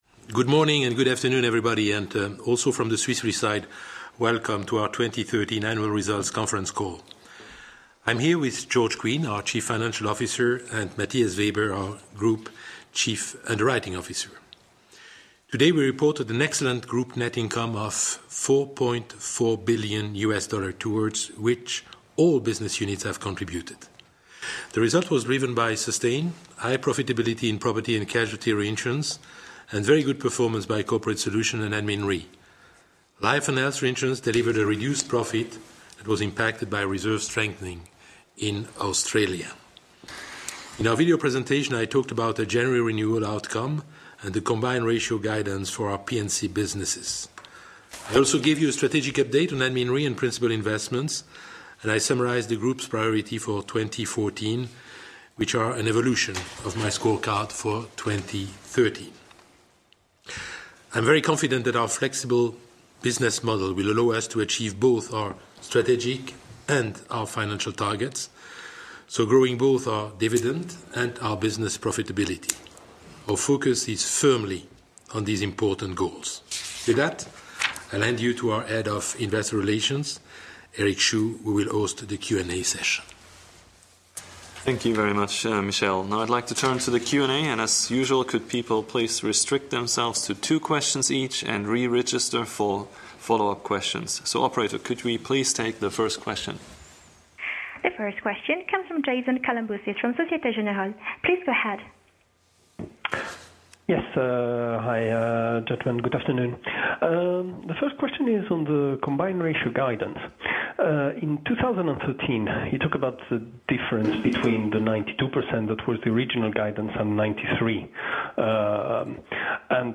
Analysts Conference call recording
2013_FY_qa_audio.mp3